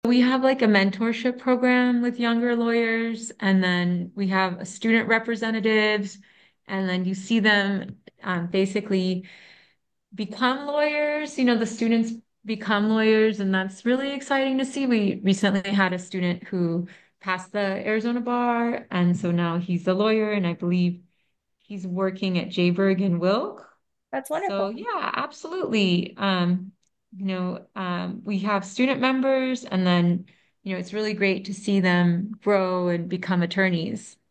Interview Clips